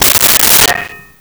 Pot Lid 02
Pot Lid 02.wav